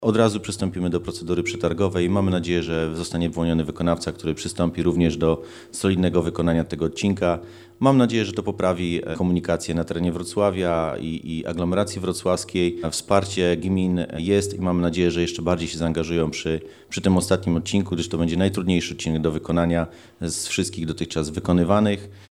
-Dołożymy wszelkich starań, by ta droga została wybudowana, podkreśla Tymoteusz Myrda, członek Zarządu Województwa Dolnośląskiego.